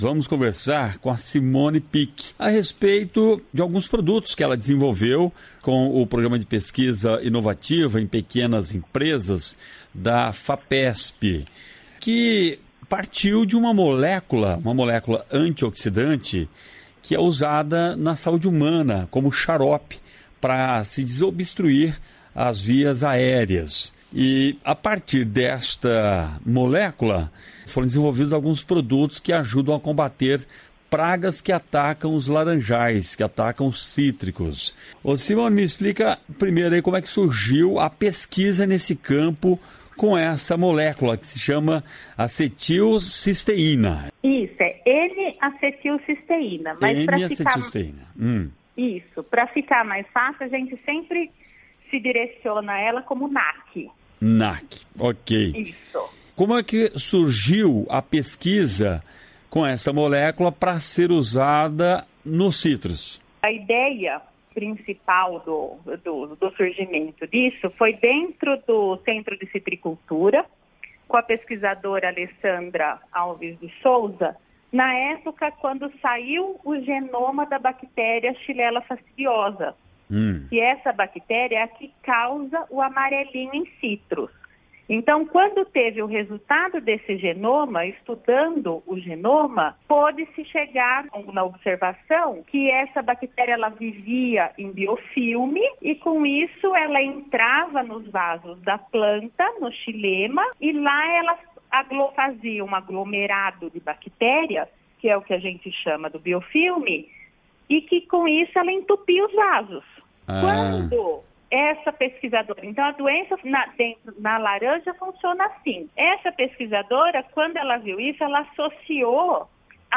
Entrevista: Conheça molécula usada em saúde humana e para controlar pragas de cítricos